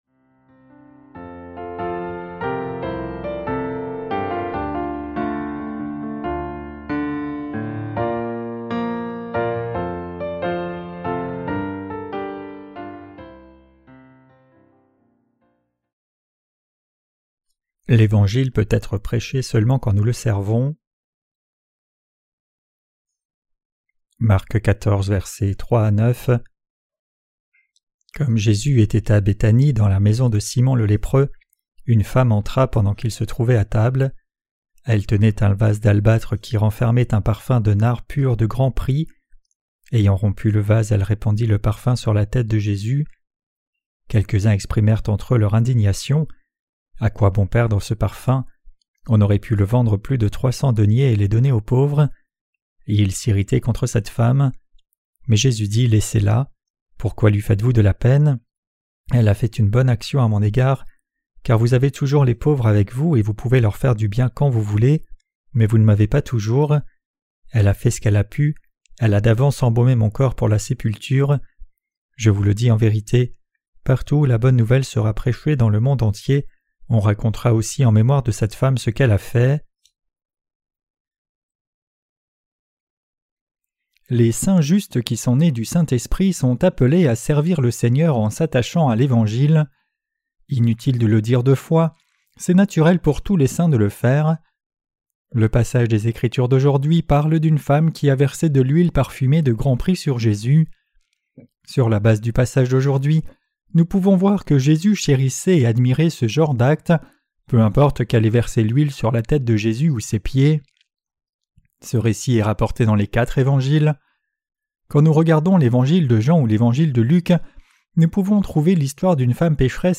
Sermons sur l’Evangile de Marc (Ⅲ) - LA BÉNÉDICTION DE LA FOI REÇUE AVEC LE CŒUR 7.